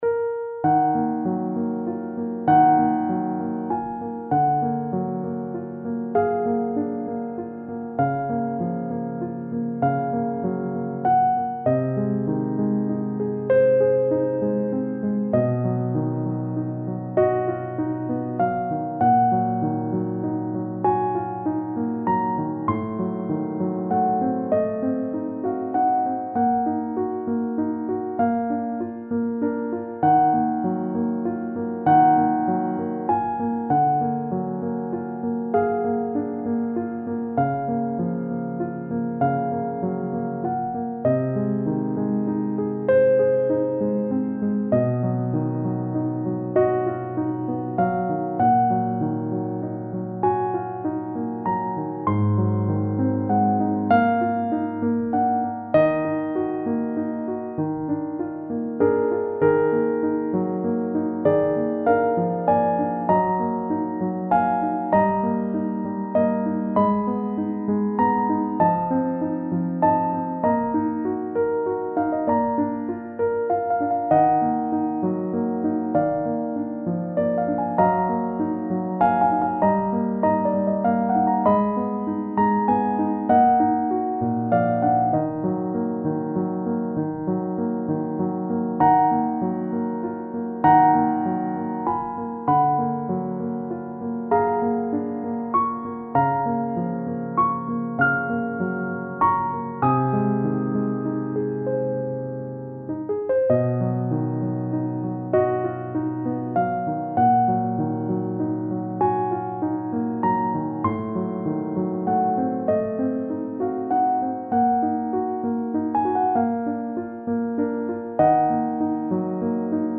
悲しい曲
ファンタジー系フリーBGM｜ゲーム・動画・TRPGなどに！
重苦しい感じなピアノ曲。